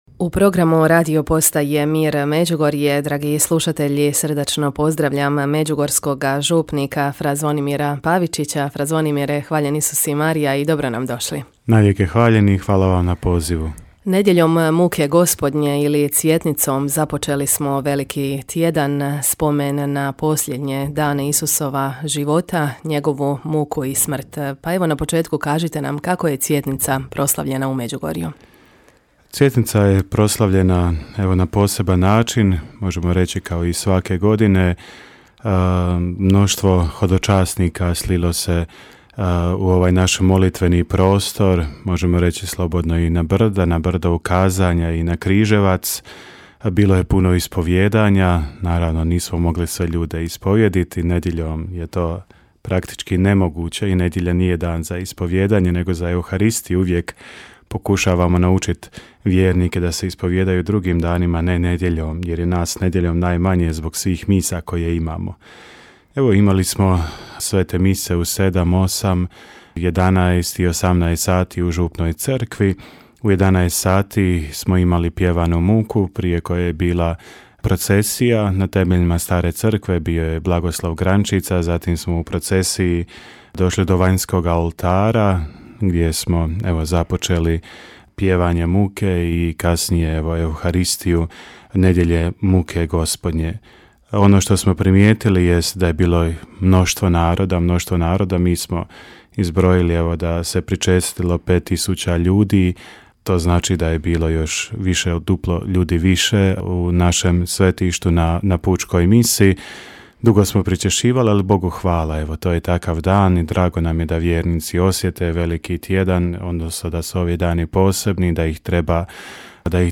Vijesti